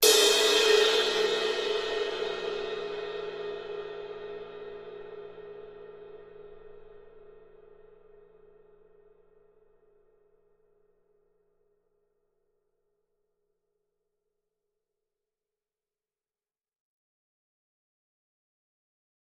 Cymbal, Large, Single Hit, Type 1